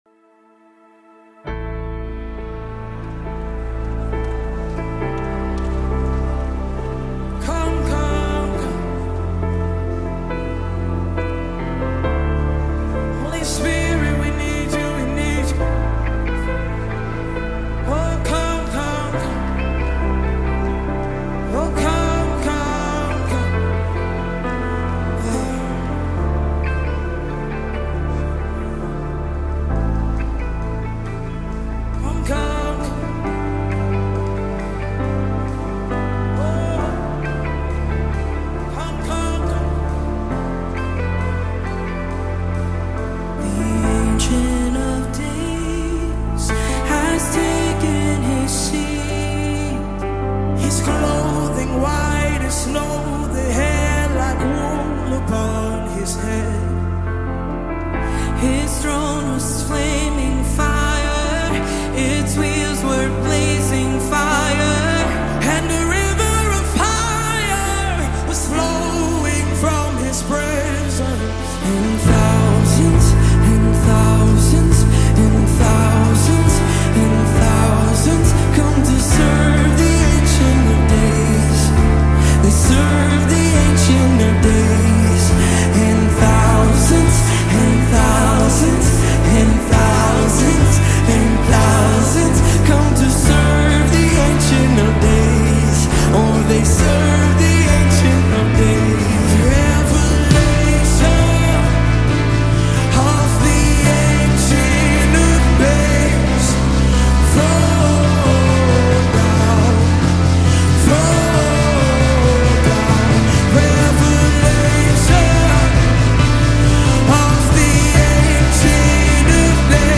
1462 просмотра 287 прослушиваний 9 скачиваний BPM: 136